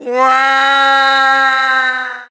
Wario goes WAAAAAAAAAAAAAAAAAHHH as he falls off in Mario Kart Wii.
Wario_(Fall_1)_Mario_Kart_Wii.oga